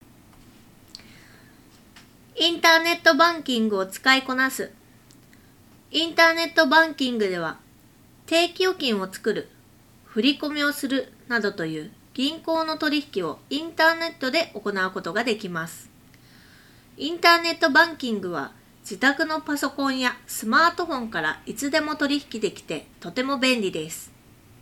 ノイキャン効果は高く、周囲のノイズを効果的に取り除き、装着者の声のみをクリアに拾い上げることができていた。
▼EarFun OpenJumpの内蔵マイクで拾った音声単体
録音音声を聴くと、周囲の環境ノイズ(空調音や屋外の音)を効果的に除去し、発言内容を明瞭に拾い上げることができていることが分かる。
earfun-openjump-earbuds-review.wav